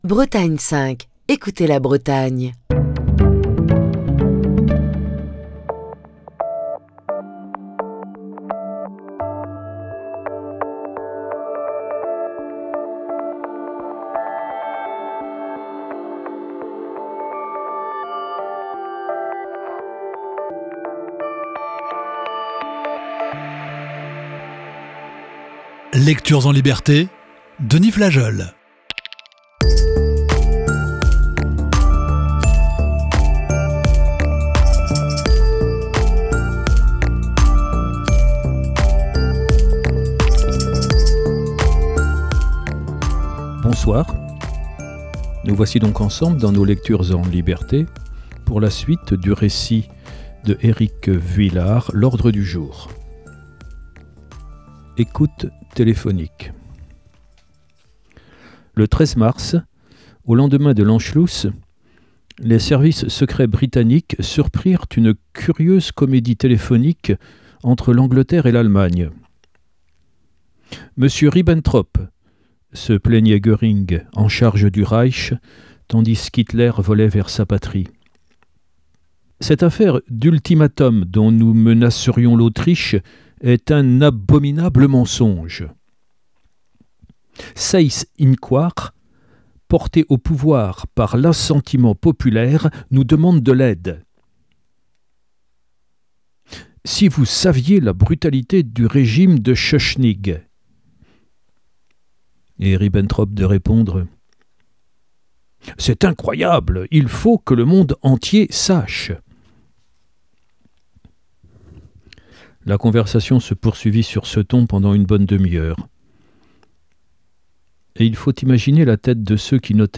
la lecture